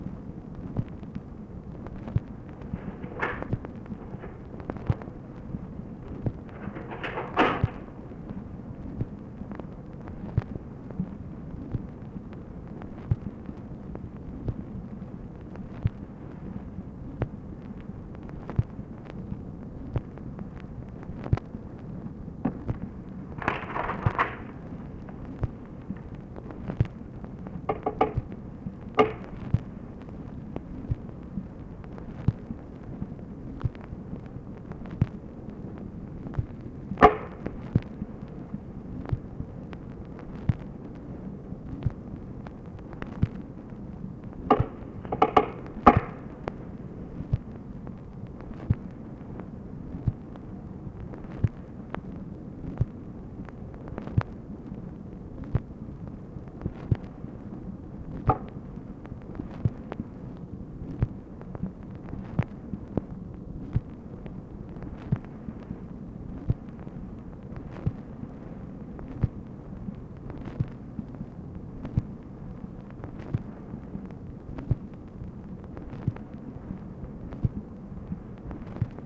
OFFICE NOISE, January 25, 1964